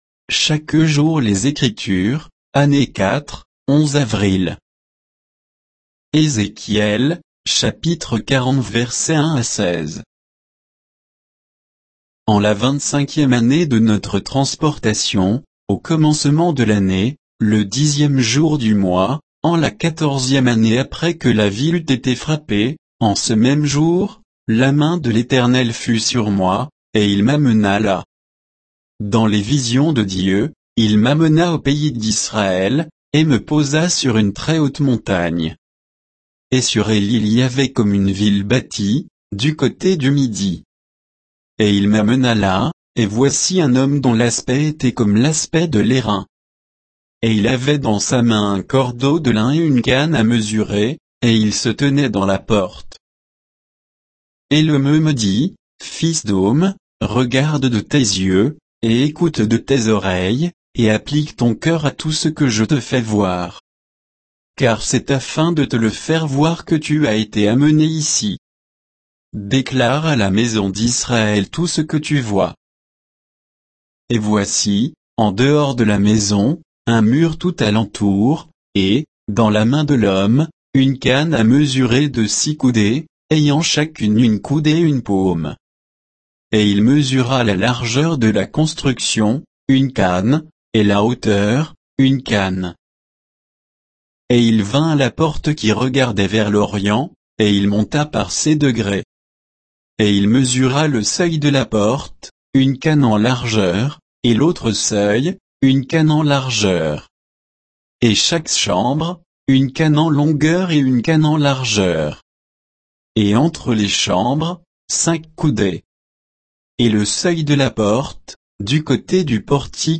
Méditation quoditienne de Chaque jour les Écritures sur Ézéchiel 40